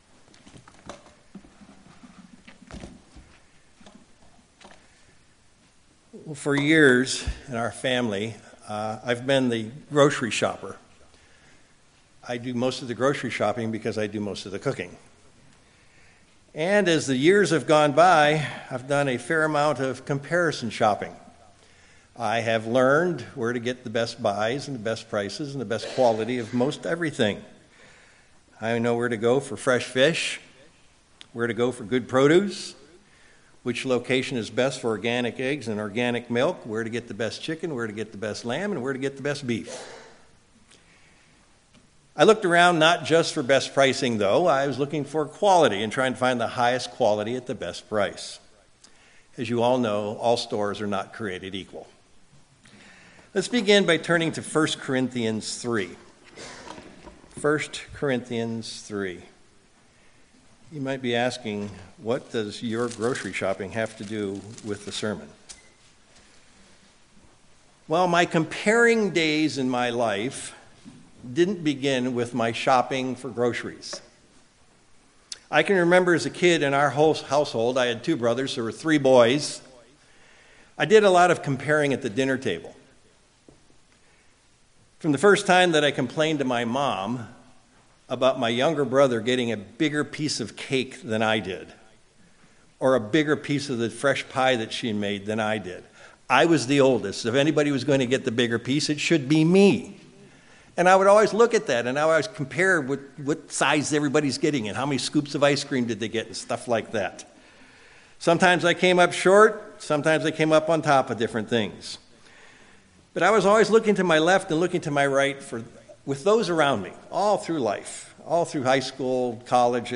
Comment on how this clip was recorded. Given in Sacramento, CA